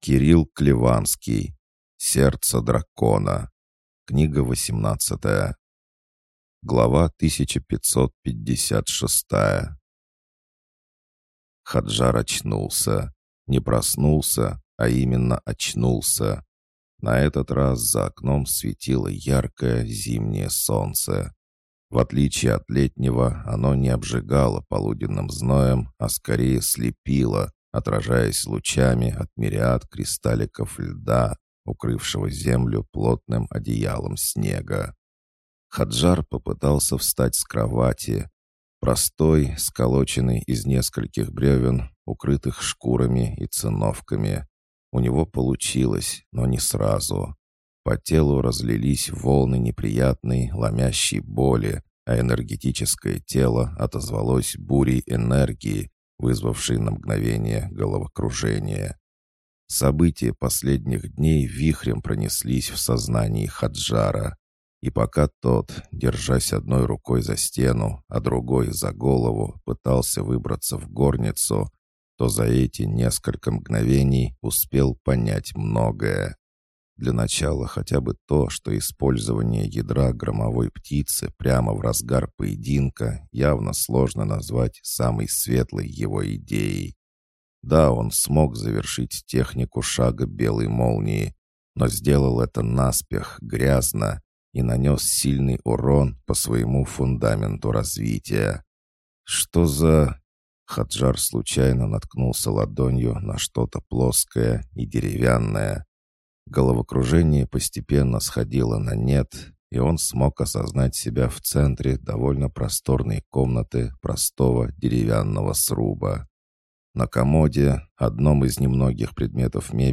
Аудиокнига боевое фэнтези, героическое фэнтези, иные миры Аудиокнига на Литрес 05 .04.23 Сердце Дракона» – фантастический роман Кирилла Клеванского, восемнадцатая книга цикла, жанр героическое фэнтези, приключения, боевое фэнтези.